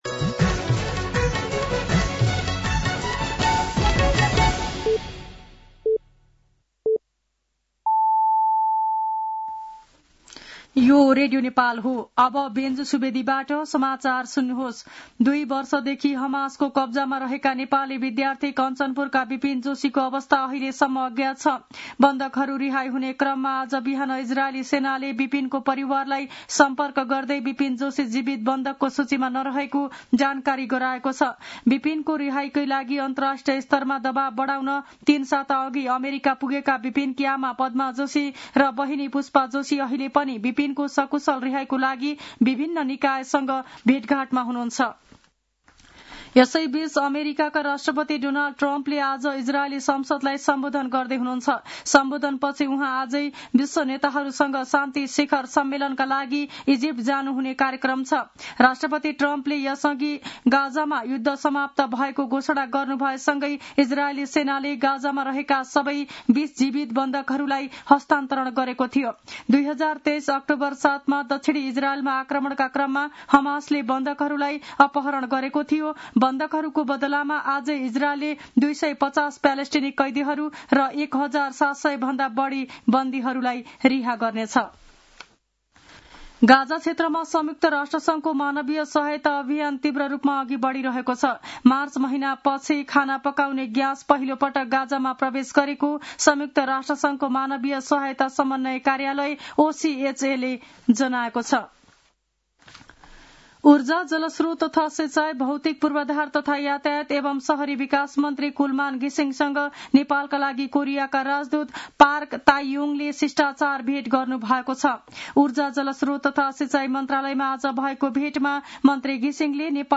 साँझ ५ बजेको नेपाली समाचार : २७ असोज , २०८२
5-pm-nepali-news-6-27.mp3